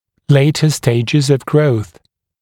[‘leɪtə ‘steɪʤɪz əv grəuθ][‘лэйтэ ‘стэйджиз ов гроус]более поздние стадии роста